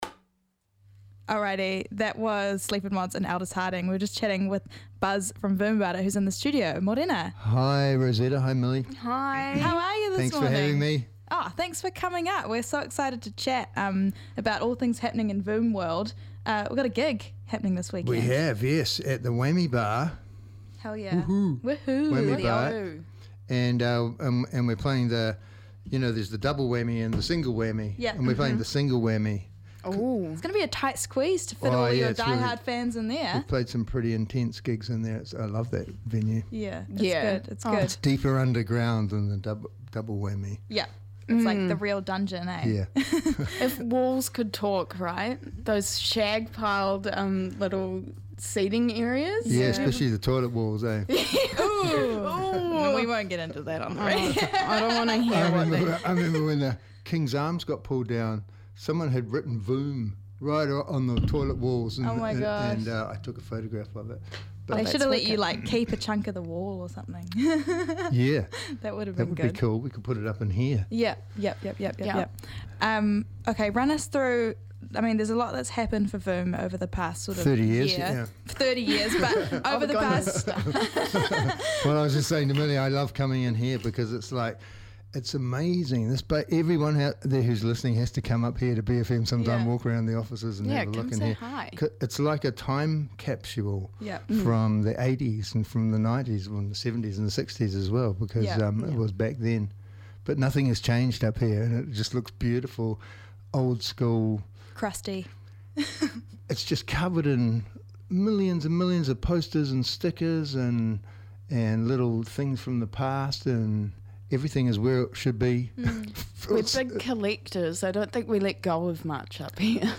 Guest Interview w/ Voom: Rāpare January 19, 2026